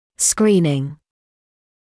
[‘skriːnɪŋ][‘скри:нин]скрининг; массовое обследование